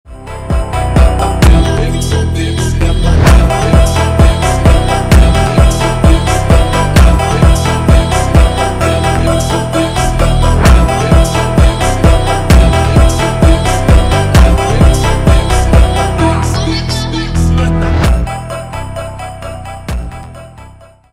с басами клубные